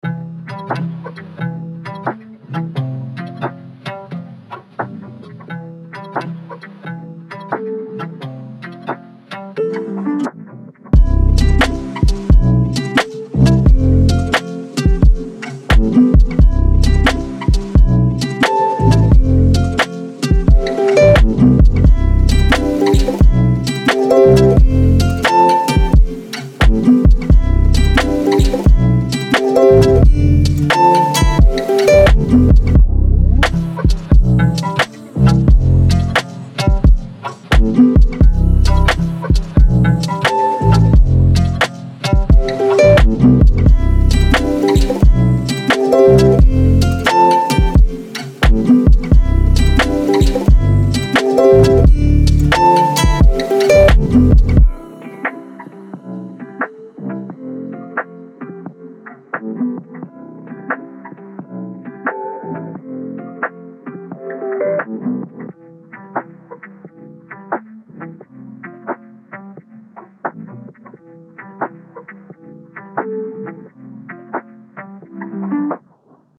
Música en mp3